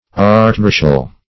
Search Result for " archmarshal" : The Collaborative International Dictionary of English v.0.48: Archmarshal \Arch`mar"shal\, n. [G. erzmarschall.